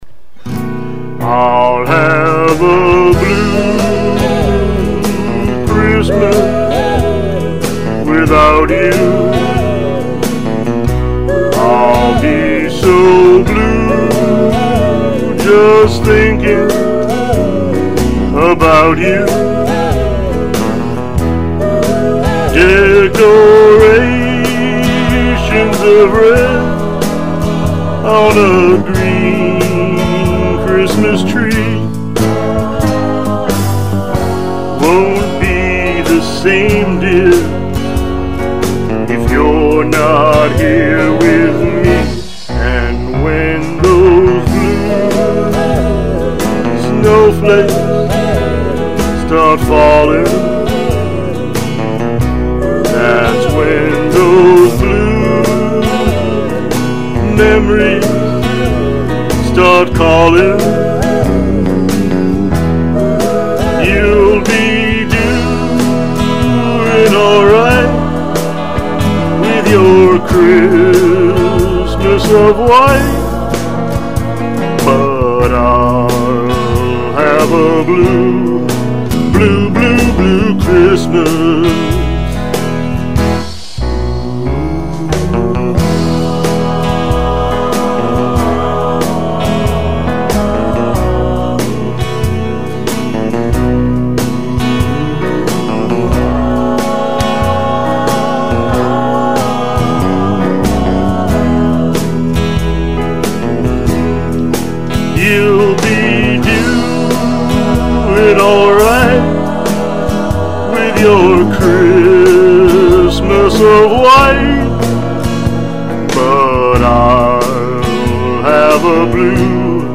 The 2006 Blogger Christmahanukwanzaakah Online Holiday Concert